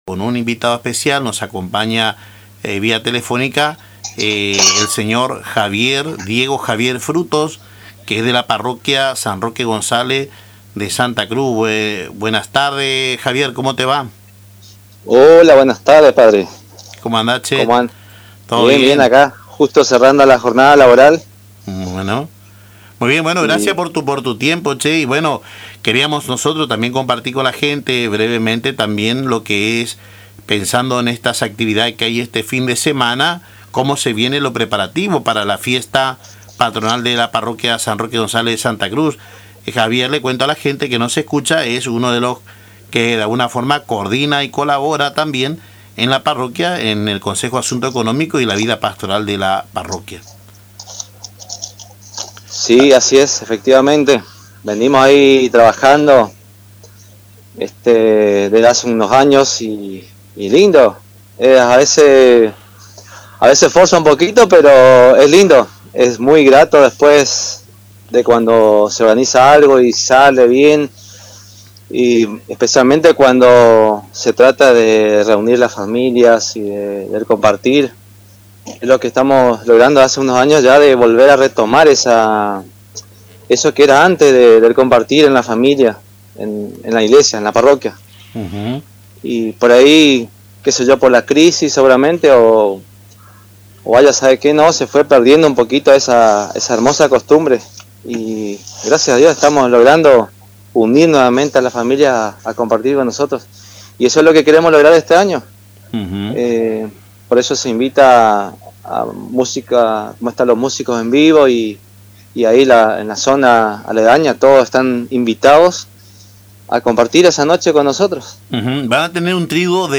La Parroquia San Roque González de Santa Cruz se encuentra en pleno período de preparación para su tradicional fiesta patronal, que este año promete reunir a la comunidad con actividades para todas las edades. En diálogo con Caminando Juntos